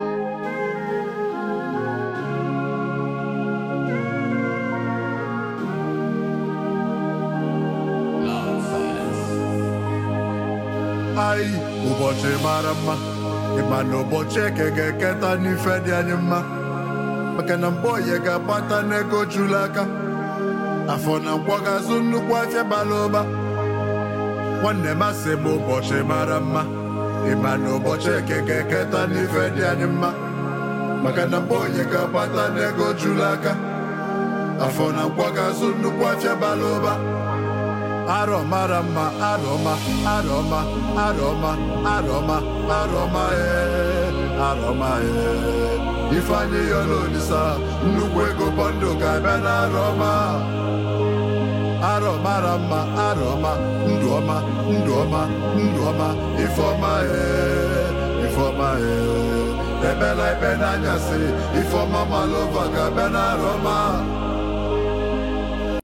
emotionally charged
With its infectious beat and captivating vocals